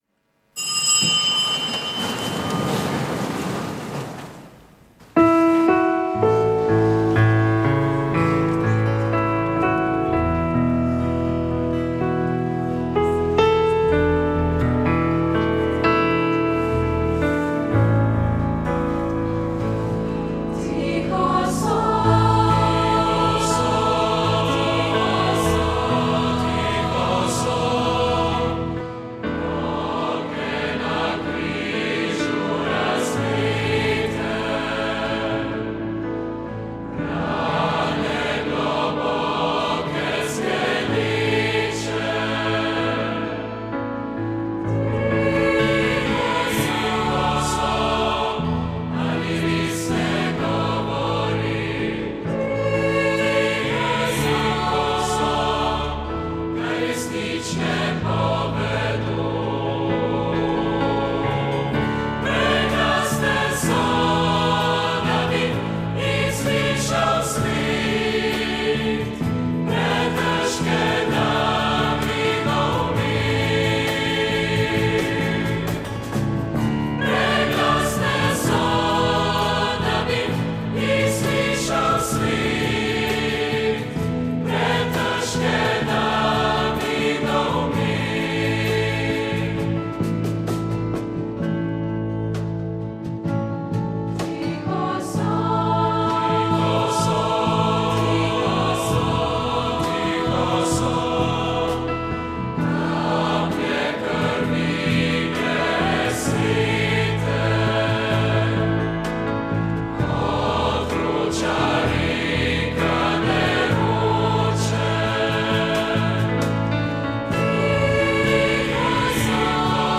Sveta maša
Sv. maša iz cerkve Marijinega oznanjenja na Tromostovju v Ljubljani 22. 3.